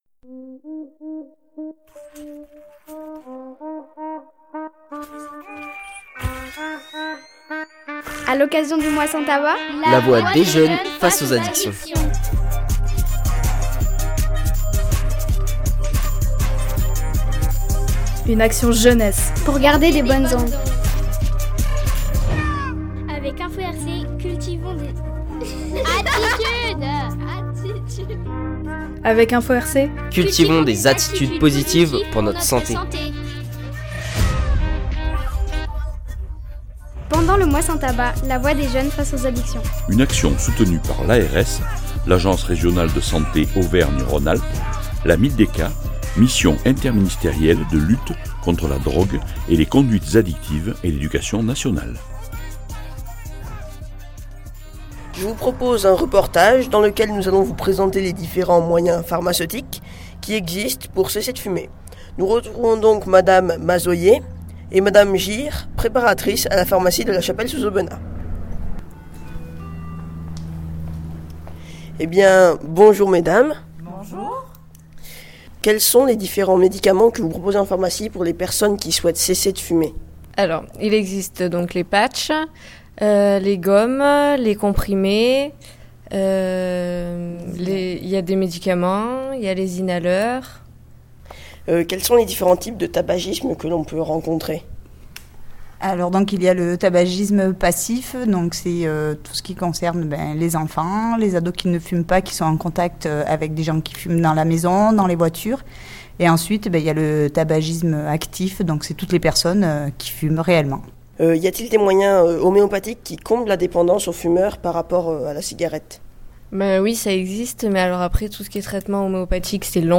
Un reportage en pharmacie présente les solutions pour arrêter de fumer. Les différentes aides disponibles et conseils pratiques y sont expliqués.